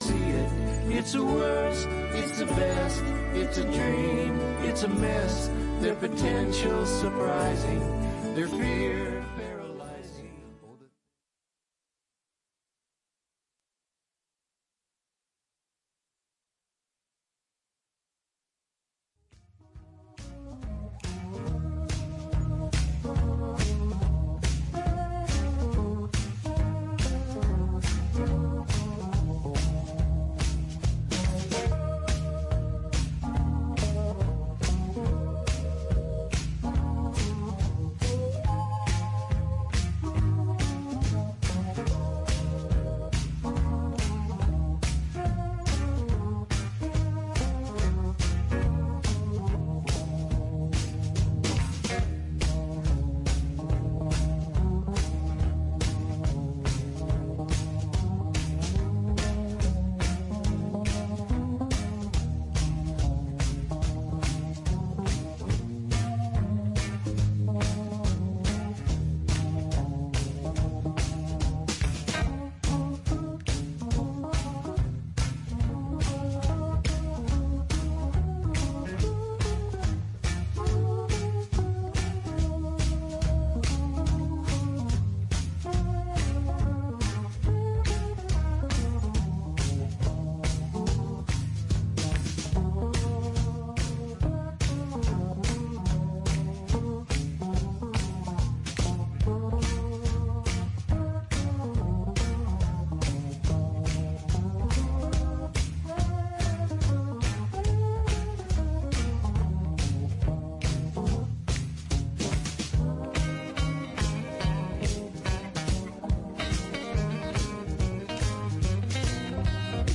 Regeneration Vermont: On the Radio